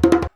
100DJEMB01.wav